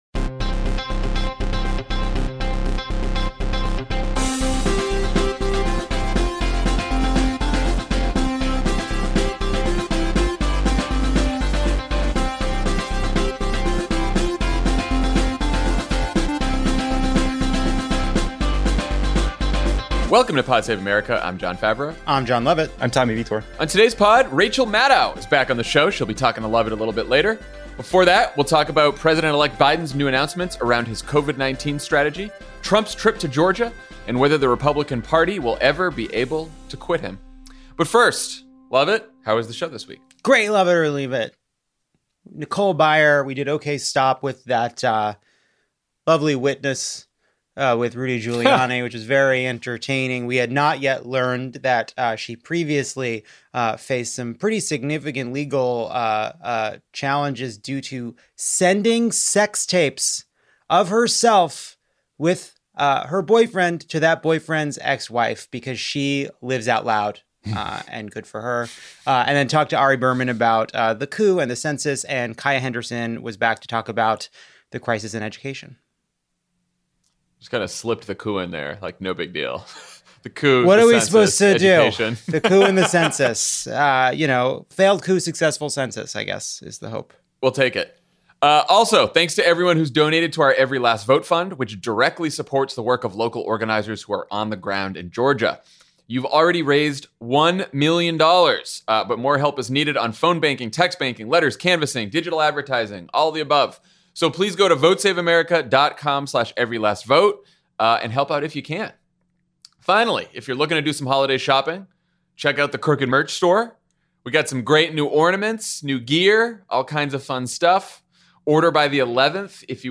President-elect Biden makes announcements about his pandemic strategy and health care team, Trump asks Georgians to vote in an election he says is rigged, and then attacks Republicans who won’t support his coup. Then Rachel Maddow talks to Jon Lovett about Covid and her new book, Bag Man.